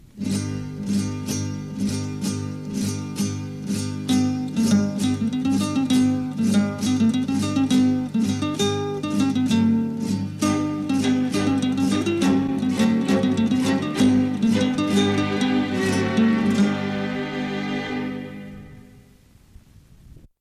versió guitarra espanyola
Estava basada en un toc militar adaptat